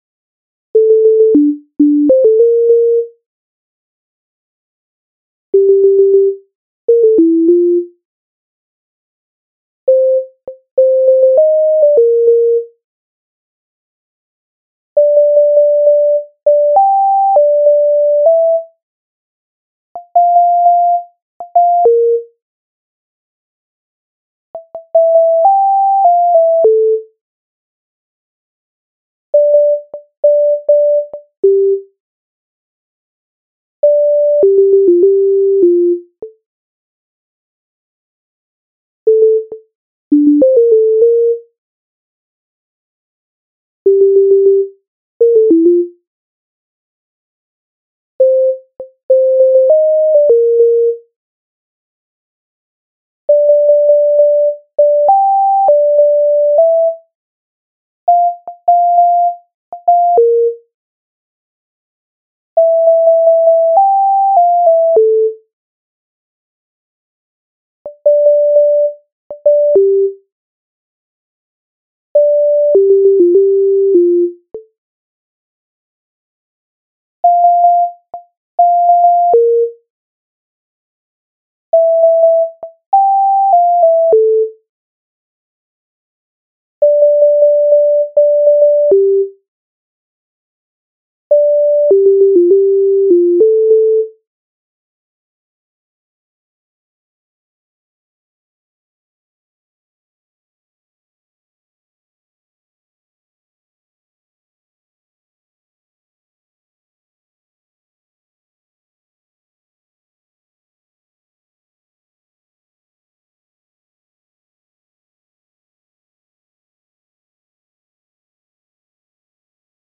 MIDI файл завантажено в тональності d-moll